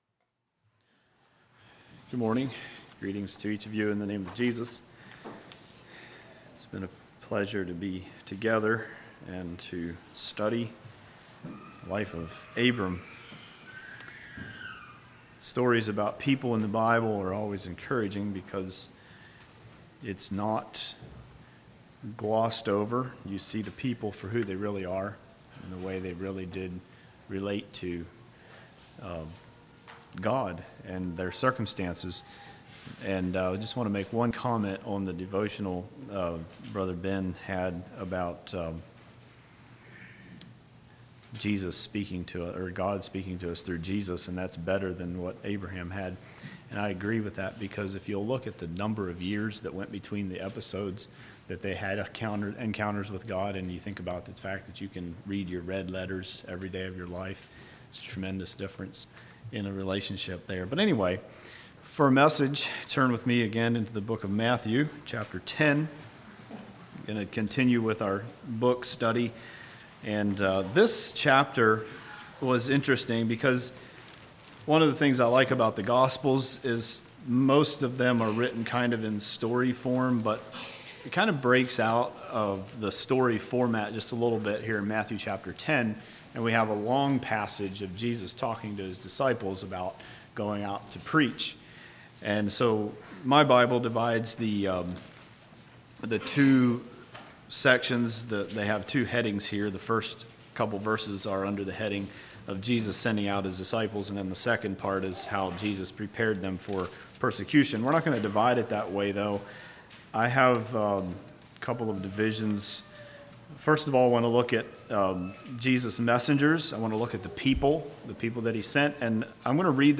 2016 Sermon ID